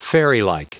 Prononciation du mot fairylike en anglais (fichier audio)
Prononciation du mot : fairylike